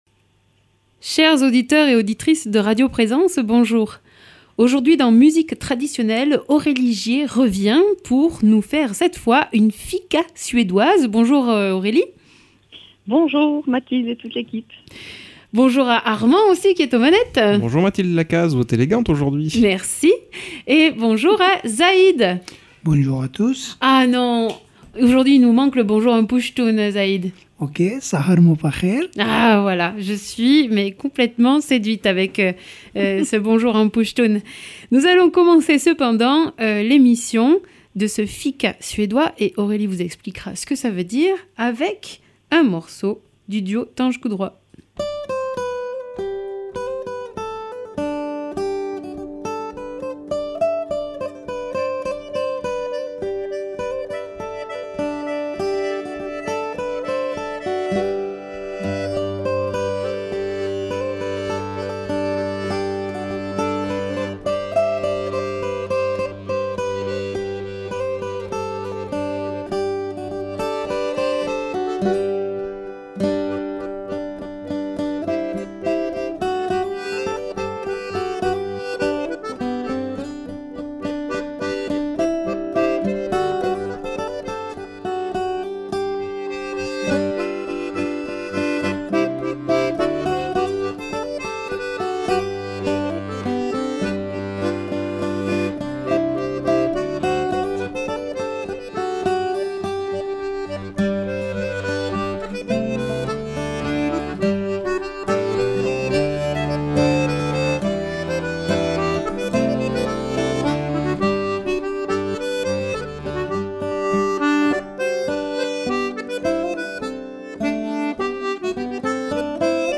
Polska, schottis et autres délices suédois sont au programme, dans une diversité de formules musicales.